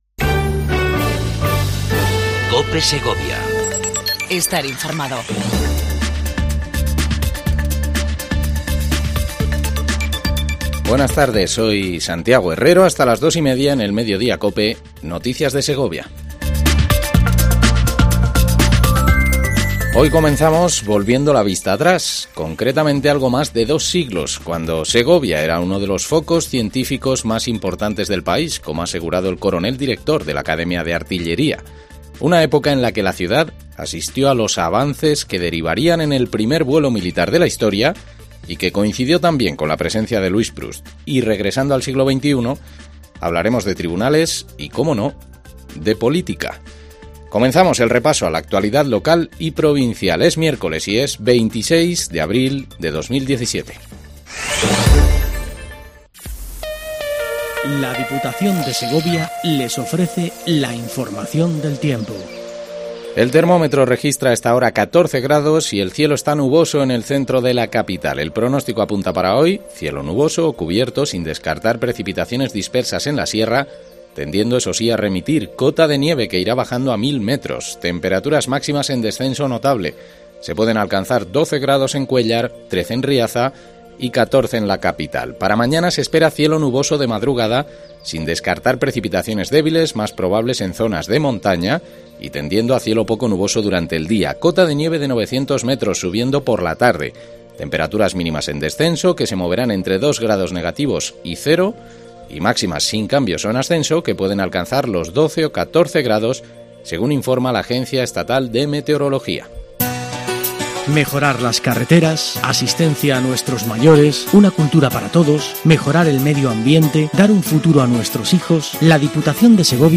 INFORMATIVO MEDIODIA COPE EN SEGOVIA 26 04 17